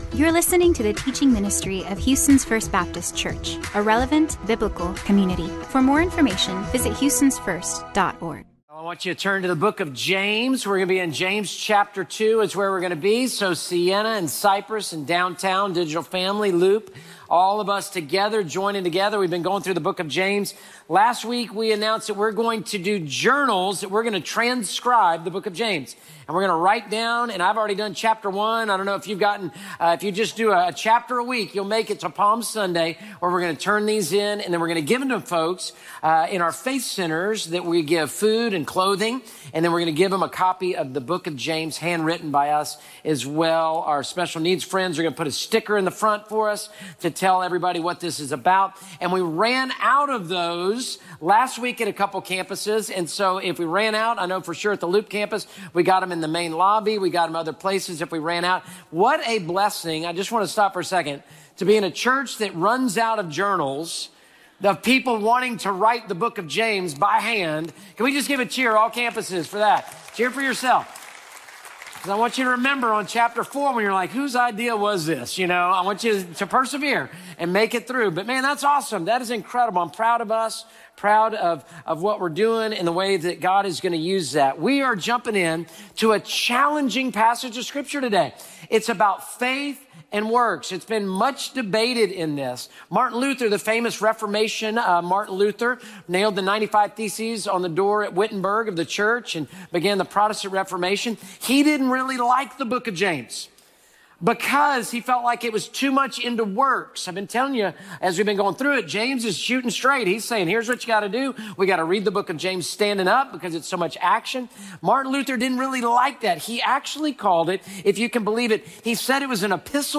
weekly messages